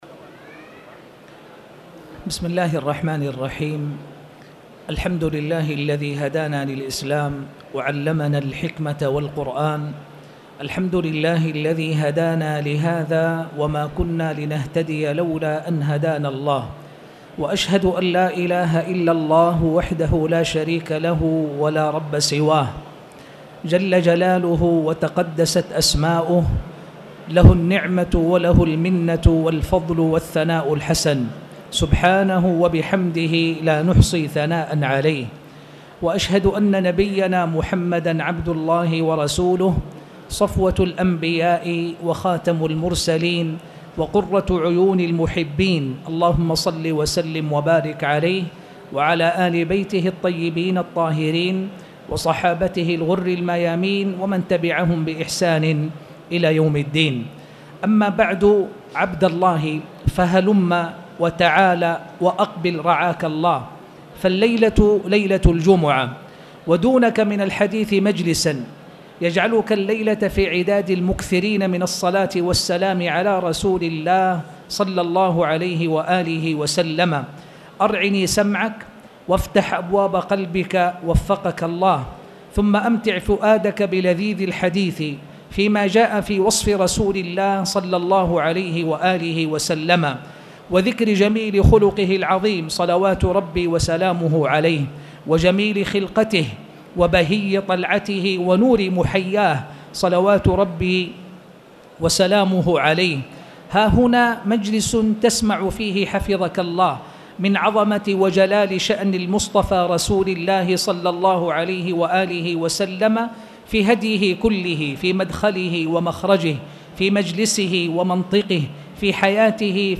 تاريخ النشر ٩ ربيع الأول ١٤٣٨ هـ المكان: المسجد الحرام الشيخ